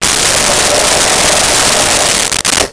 Blow Dryer 04
Blow Dryer 04.wav